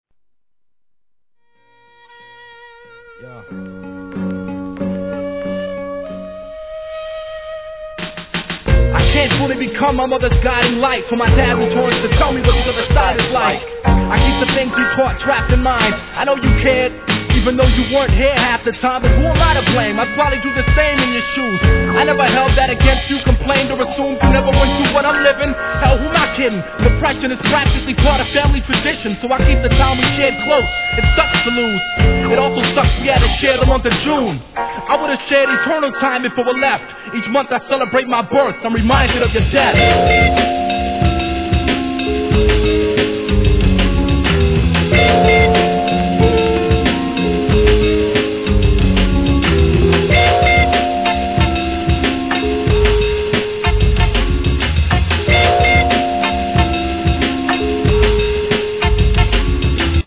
HIP HOP/R&B
アコースティックのメランコリーなメロディーとシンセノ絶妙な絡み。